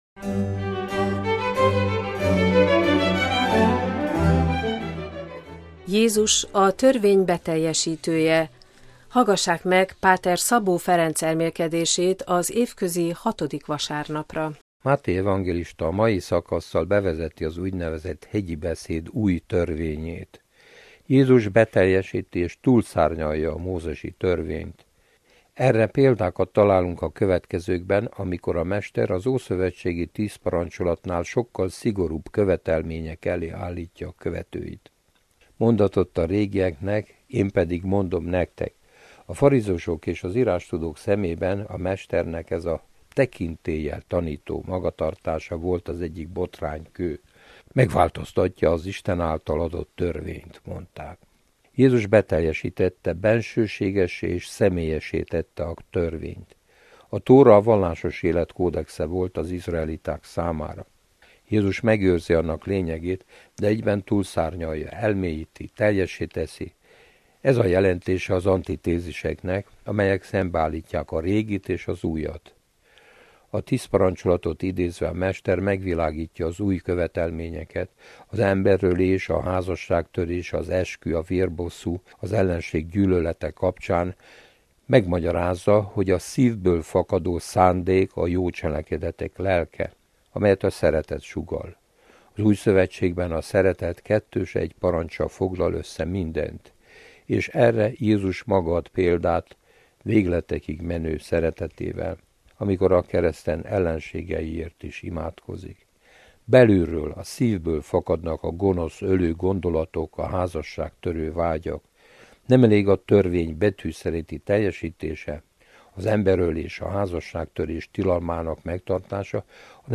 elmélkedése az évközi 6. vasárnapra